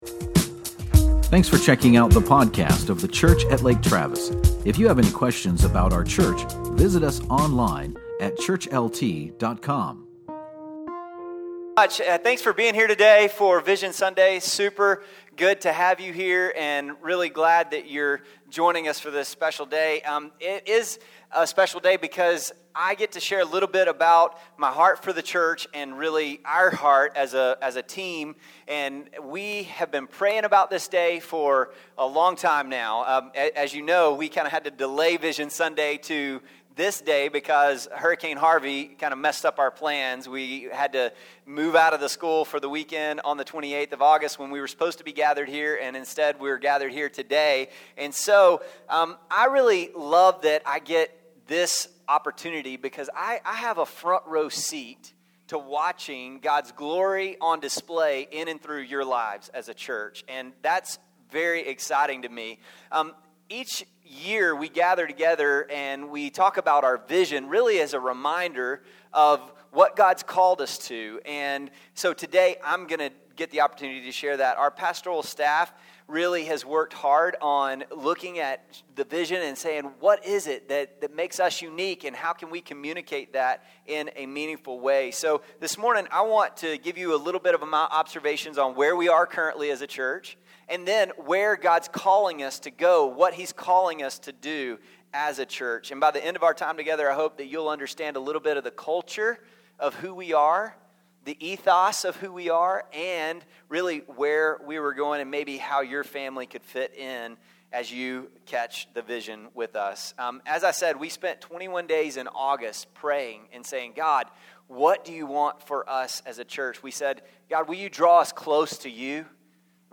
VISION SUNDAY SEIZE EVERY OPPORTUNITY